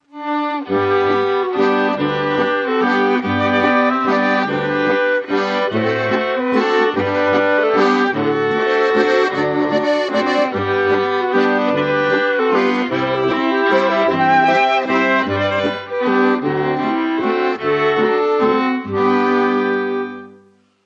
Musik aus dem Mostviertel